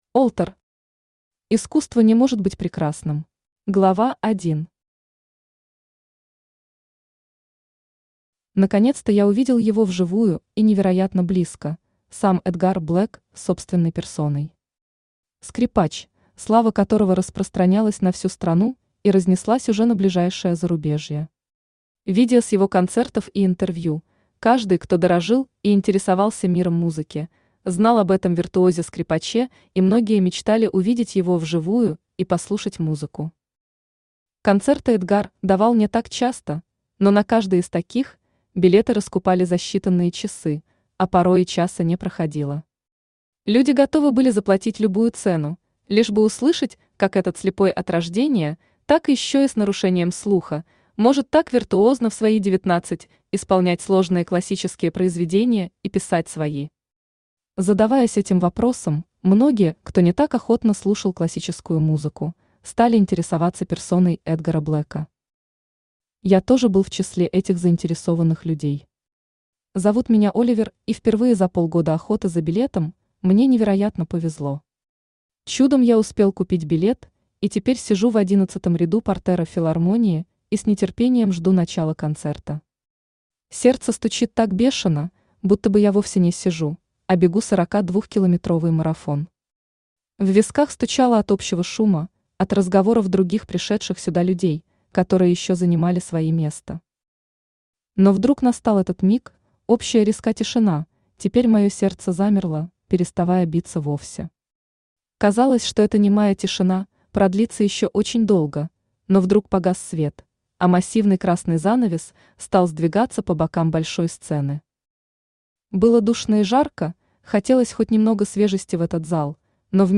Аудиокнига Искусство не может быть прекрасным | Библиотека аудиокниг
Aудиокнига Искусство не может быть прекрасным Автор Alter Читает аудиокнигу Авточтец ЛитРес.